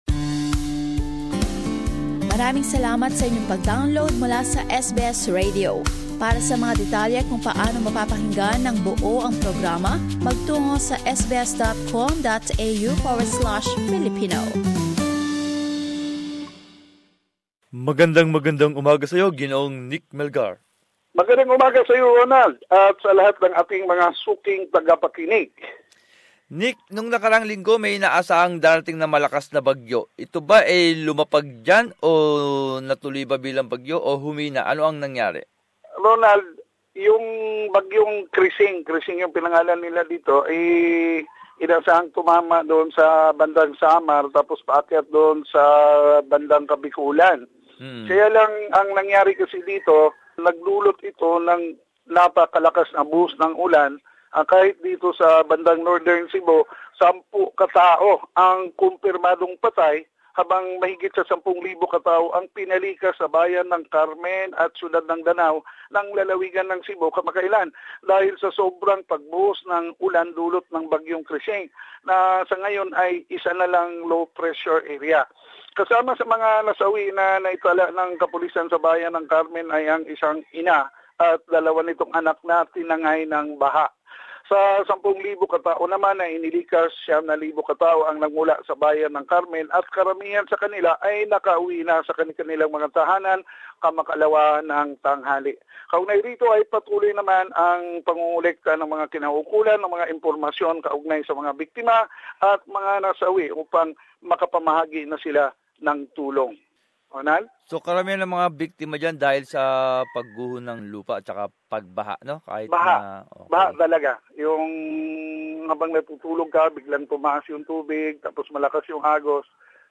Balitan Bisayas. Buod ng mga pinakahuling balita mula sa rehiyon